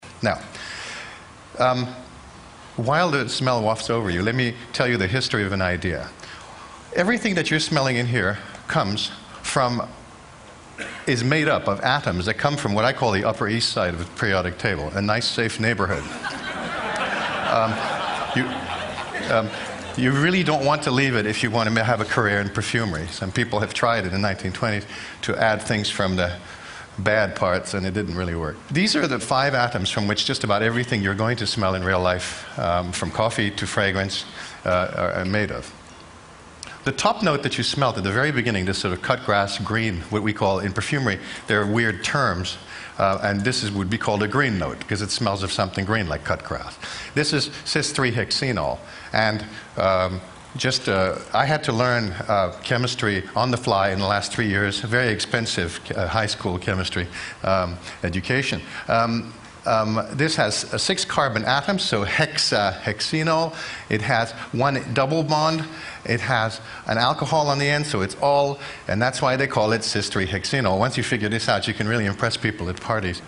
TED演讲：气味背后的科学(2) 听力文件下载—在线英语听力室